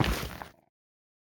Minecraft Version Minecraft Version latest Latest Release | Latest Snapshot latest / assets / minecraft / sounds / block / shroomlight / step3.ogg Compare With Compare With Latest Release | Latest Snapshot
step3.ogg